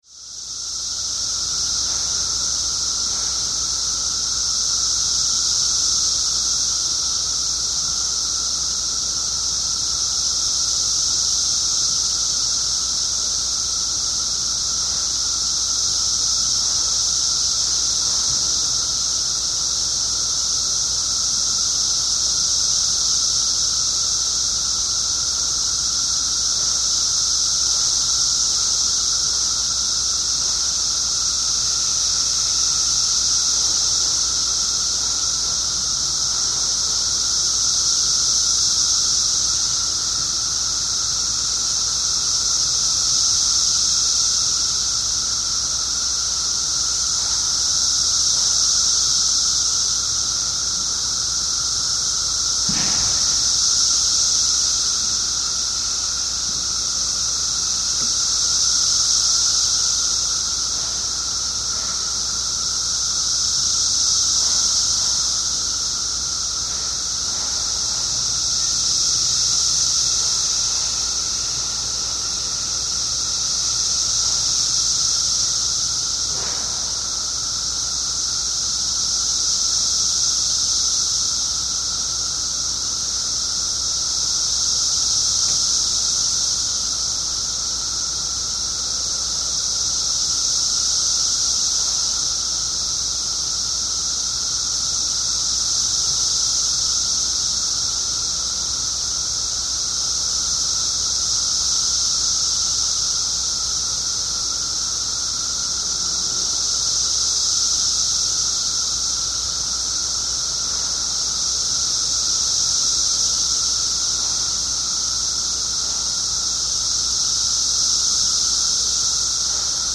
Excerpts of the 17-year Cicada Brood XIII from Glenview, IL (2007)
(N.B. Several factors influenced the intensity of male chirping. The total population of cicadas peaked in mid-June. Activity increased with higher daily temperatures and rising sun, while precipitation along with cooler air brought about a decrease or absence of chirping.)